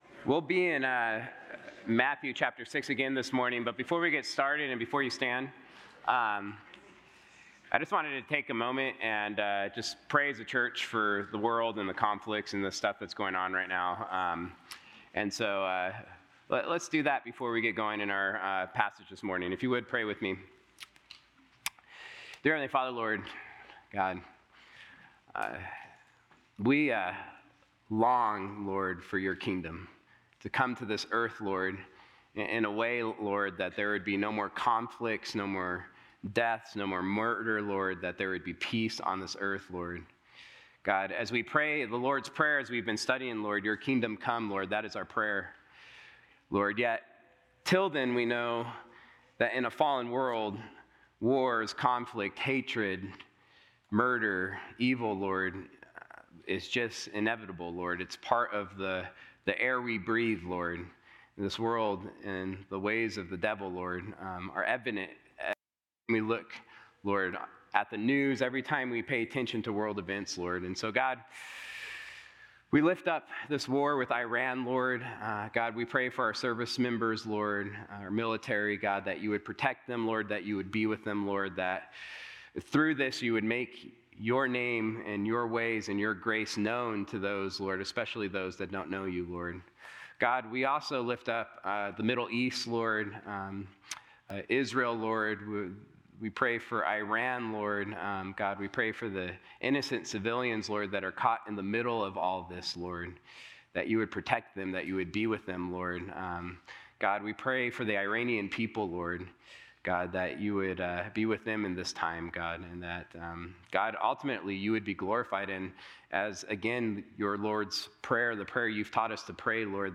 Sunday-Sermon-March-8-2026.mp3